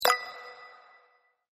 button.mp3